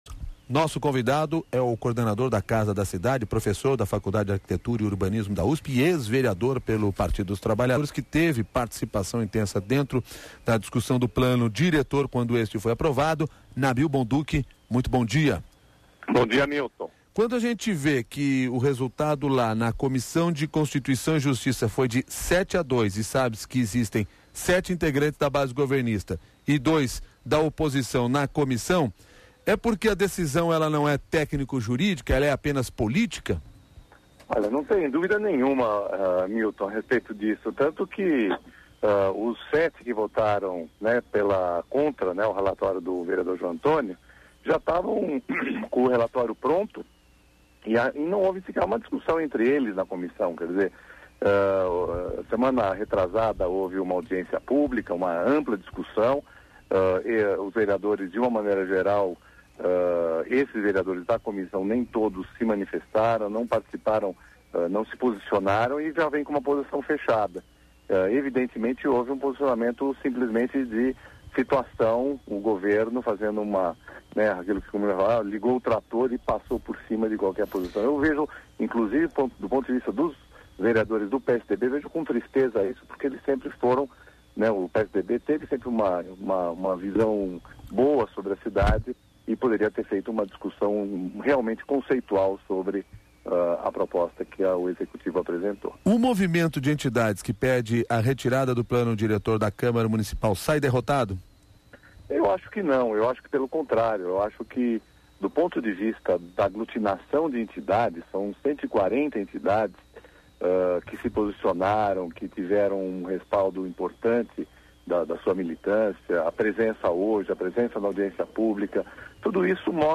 Ouça a entrevista do arquiteto Nabil Bonduki ao CBN SP